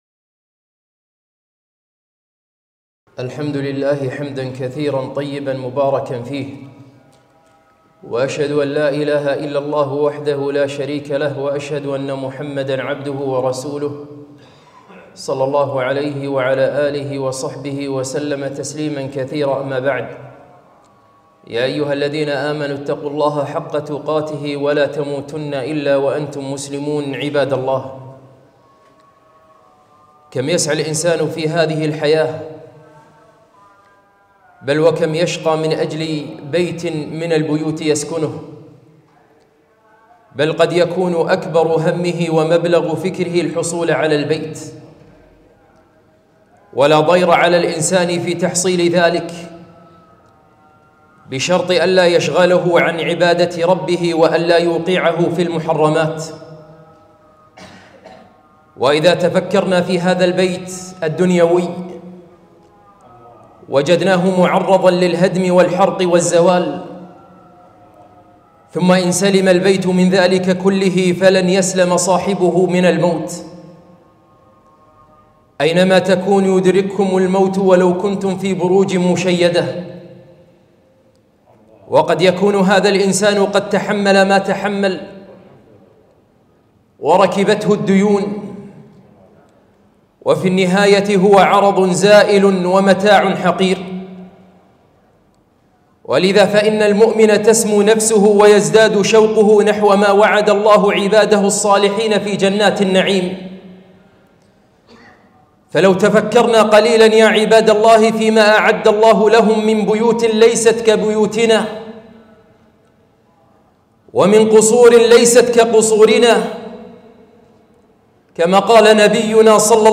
خطبة - هل تريد بيتا في الجنة ؟